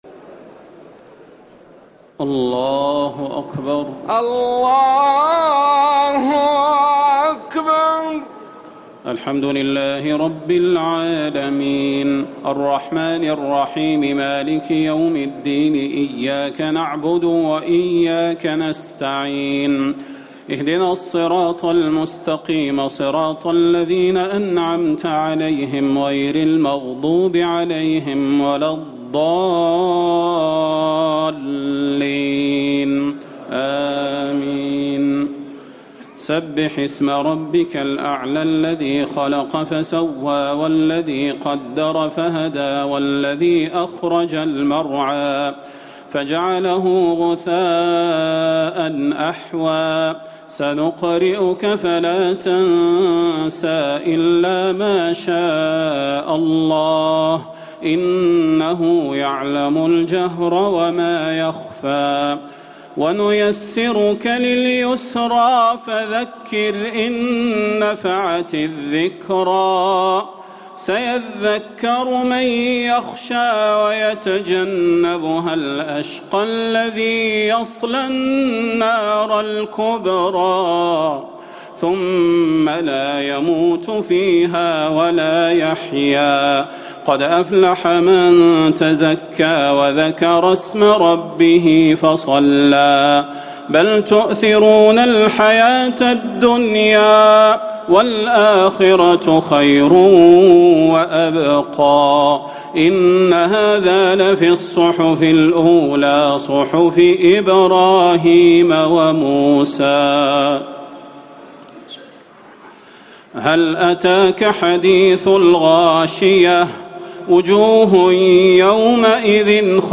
تراويح ليلة 29 رمضان 1439هـ من سورة الأعلى الى الناس Taraweeh 29 st night Ramadan 1439H from Surah Al-A'laa to An-Naas > تراويح الحرم النبوي عام 1439 🕌 > التراويح - تلاوات الحرمين